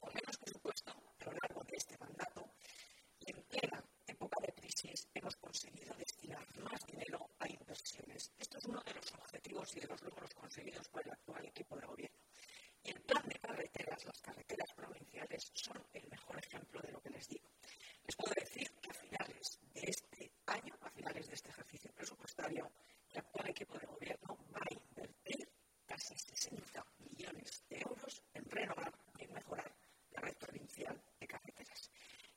El anuncio lo ha realizado la presidenta de la Diputación, Ana Guarinos, durante una rueda de prensa de balance del Plan de Carreteras